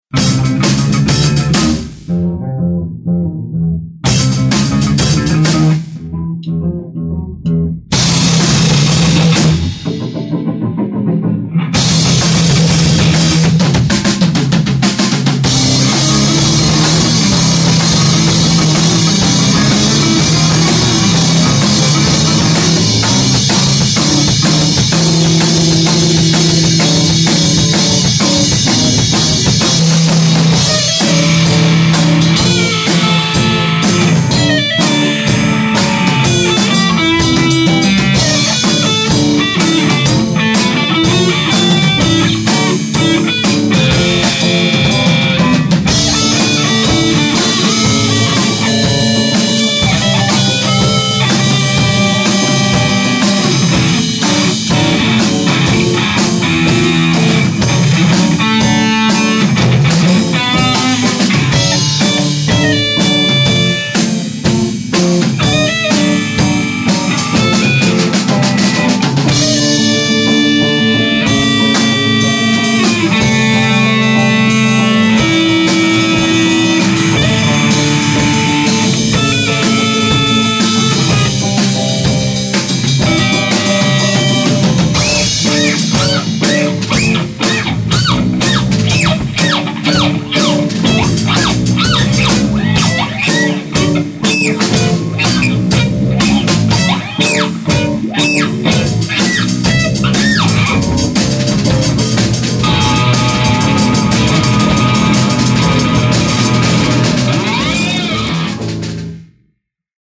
live jam - September 2005